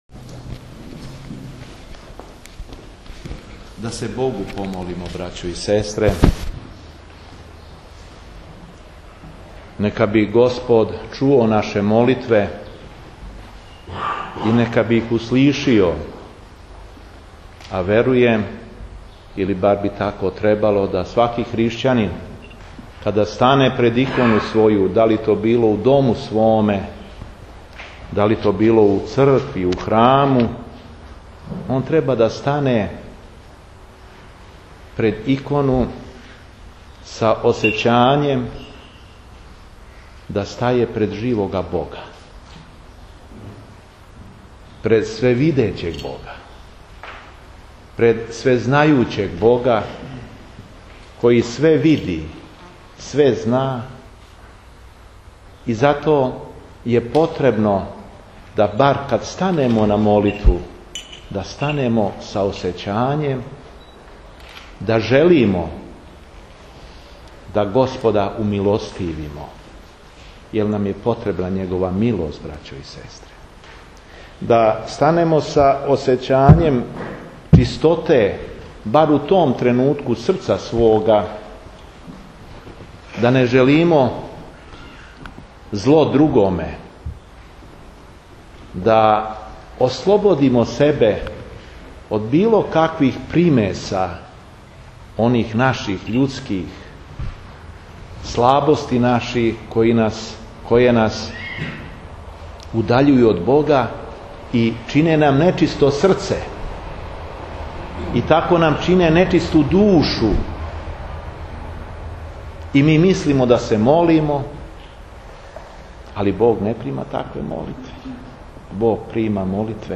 Беседа епископа шумадијског Г. Јована